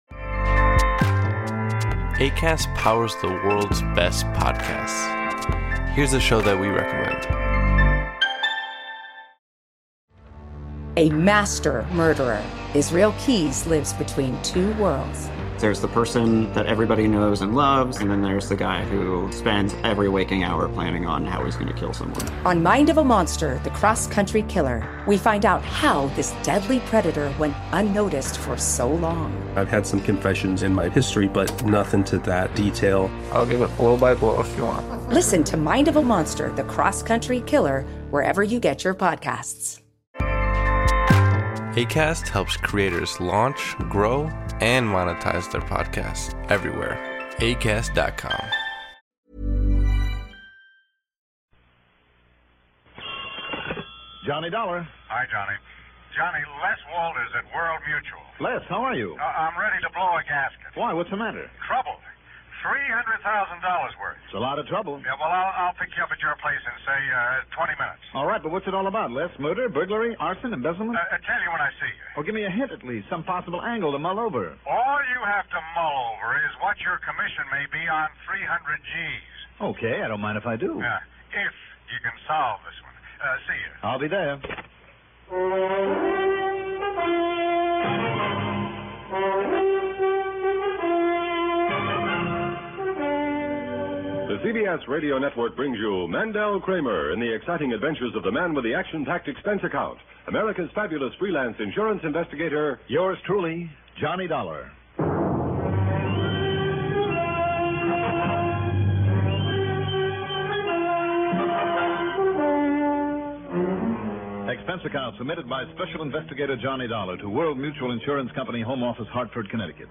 Yours Truly, Johnny Dollar was a radio drama that aired on CBS Radio from February 18, 1949, to September 30, 1962. The first several seasons imagined protagonist Johnny Dollar as a standard private investigator drama.